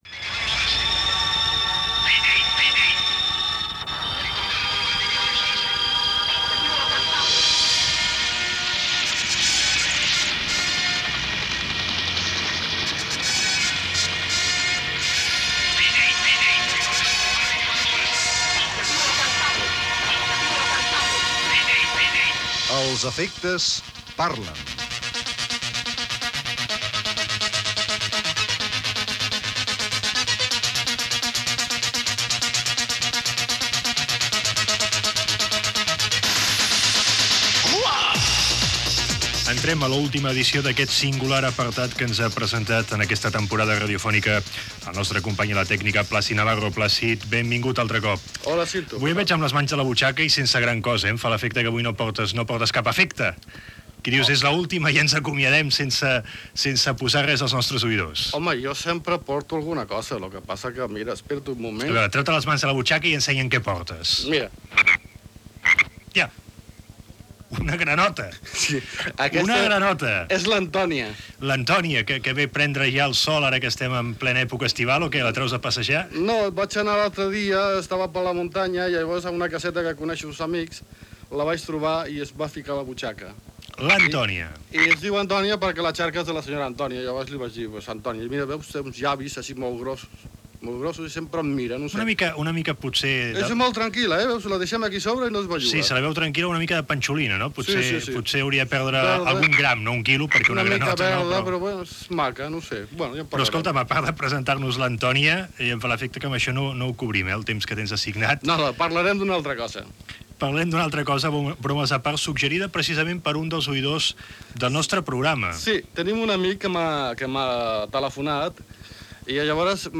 sintonies de programes de ràdio
Divulgació